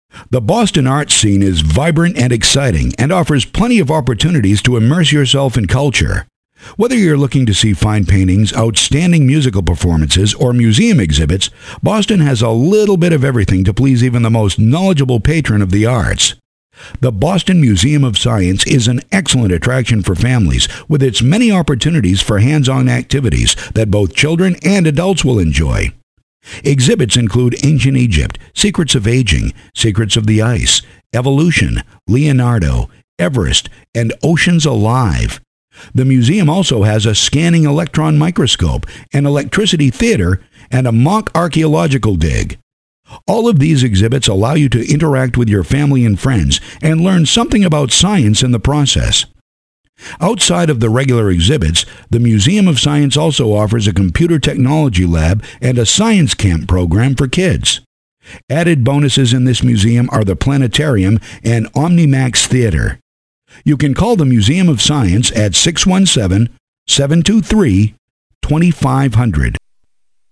Boston Audio Travel Guide